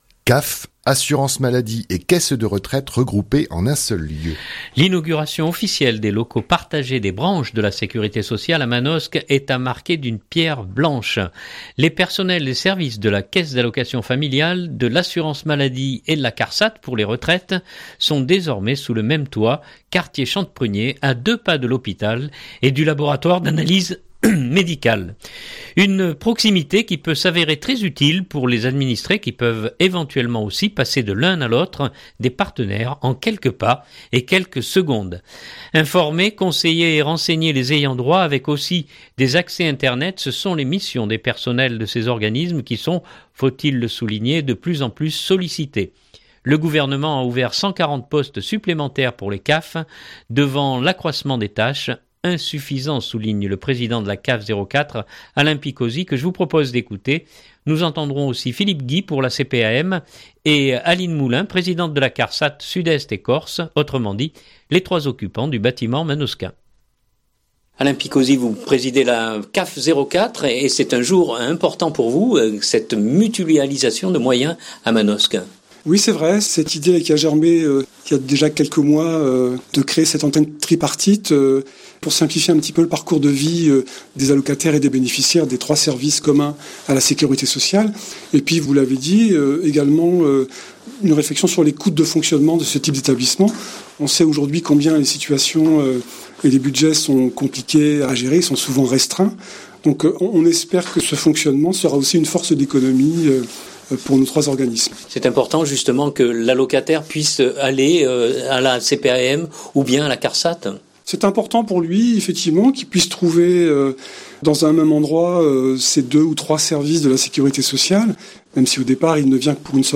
2019-03-13-reportage-CAF.mp3 (3.24 Mo)